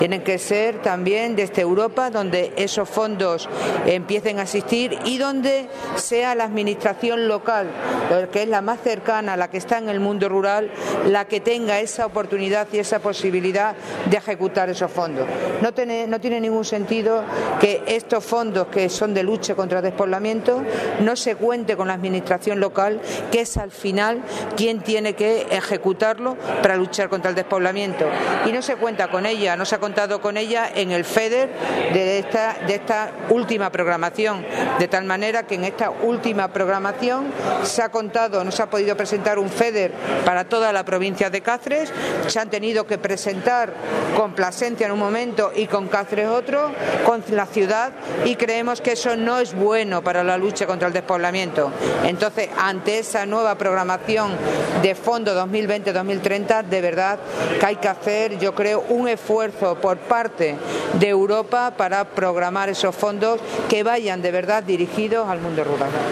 CORTES DE VOZ
Cáceres.- La presidenta de la Diputación de Cáceres, Rosario Cordero, ha sido la encargada de inaugurar las Jornadas sobre Fondos de la Unión Europea para Sostenibilidad y Territorios Inteligentes, que organiza la Oficina Europa, de la propia Institución provincial, con la colaboración de la Federación de Municipios y Provincias de Extremadura (FEMPEX), unas jornadas que reúnen a representantes de los distintos ayuntamientos de la provincia, de entidades locales, mancomunidades, grupos de Acción Local, empresas y otros agentes sociales interesados.